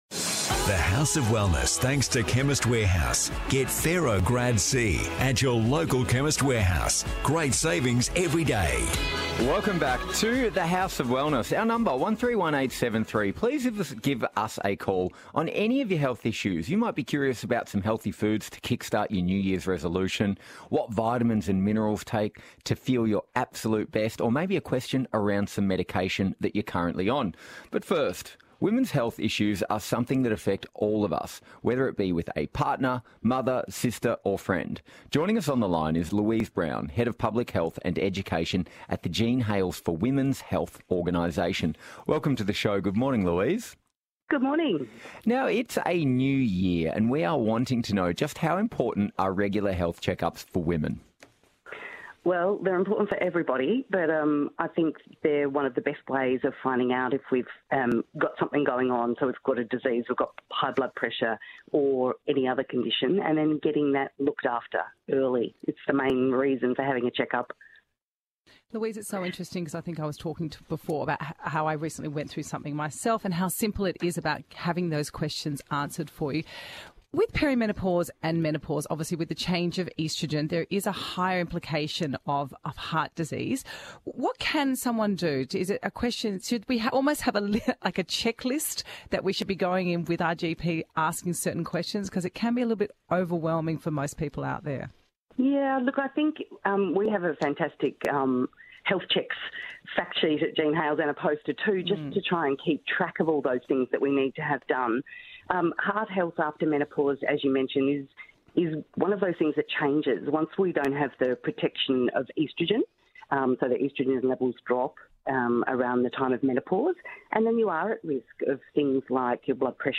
discusses women’s health on The House of Wellness radio show